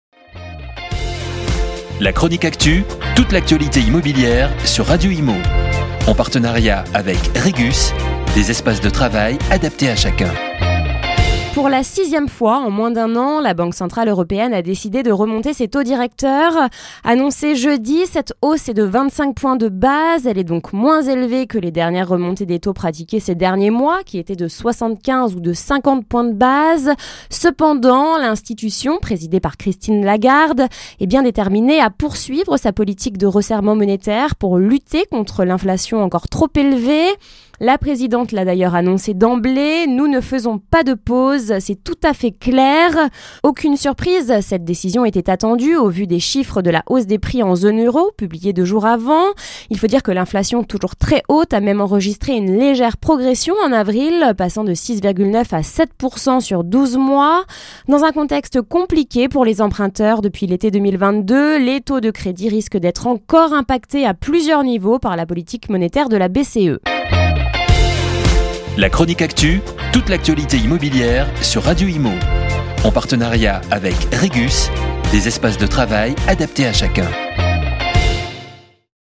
Chronique Actualité